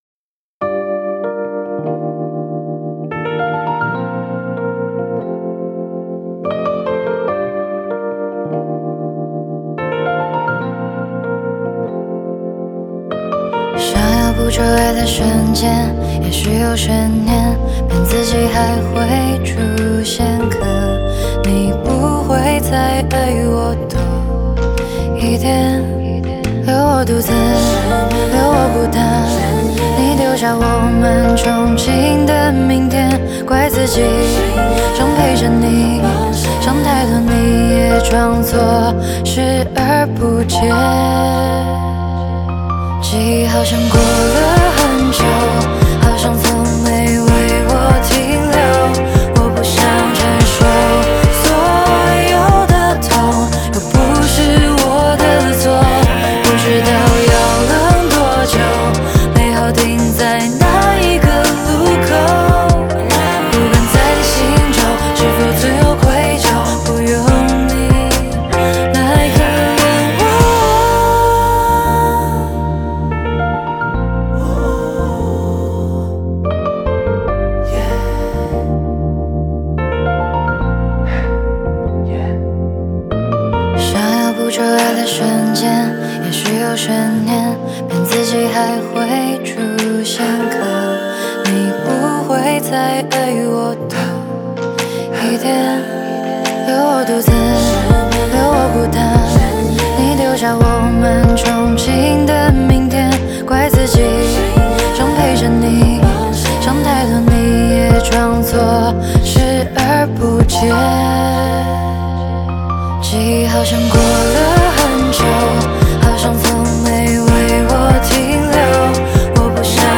Ps：在线试听为压缩音质节选，体验无损音质请下载完整版
和音